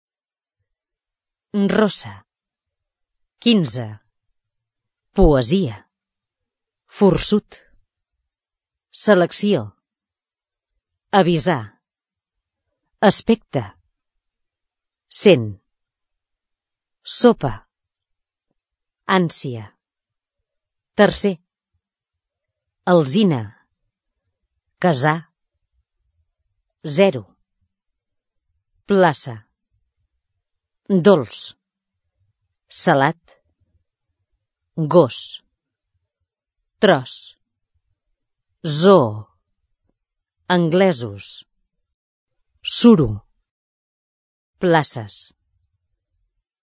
Pots fer-ho en l’activitat següent, intenta distingir el so sord o sonor de la essa d’una sèrie de paraules.
text oral